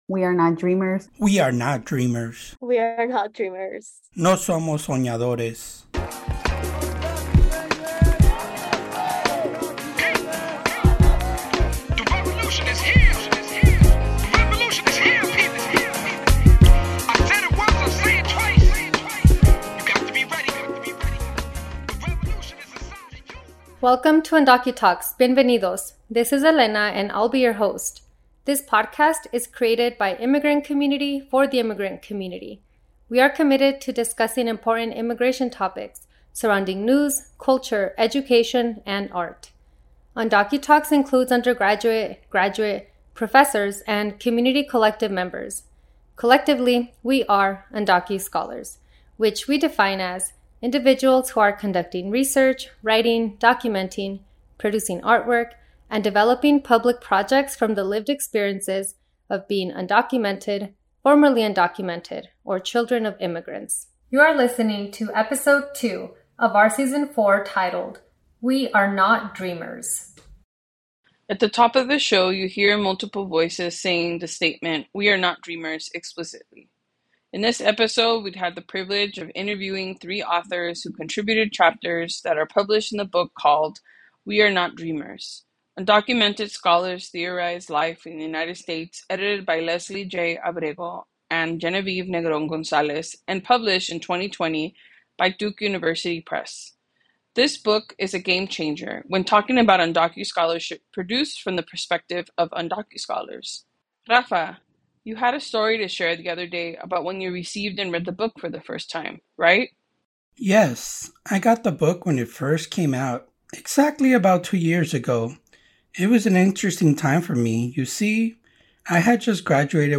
We feature 3 interviews with three of the chapters in the book: